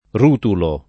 vai all'elenco alfabetico delle voci ingrandisci il carattere 100% rimpicciolisci il carattere stampa invia tramite posta elettronica codividi su Facebook rutulo [ r 2 tulo ] (antiq. rutolo [ r 2 tolo ]) etn. stor.